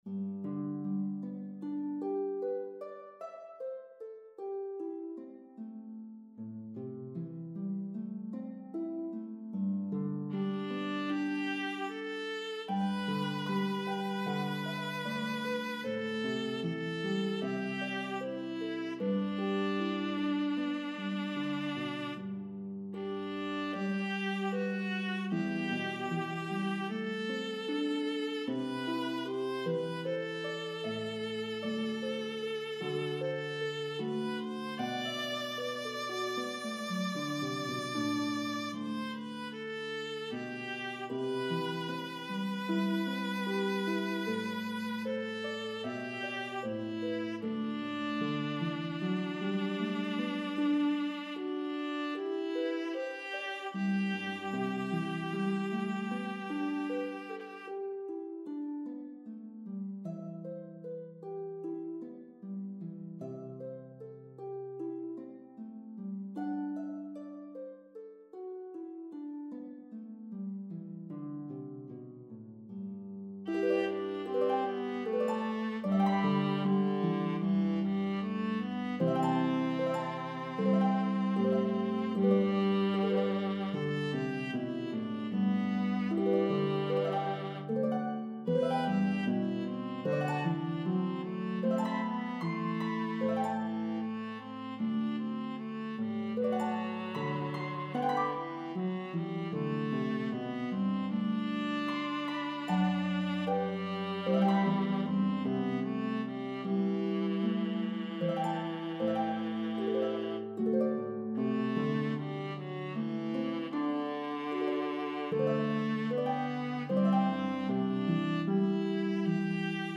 Harp and Viola version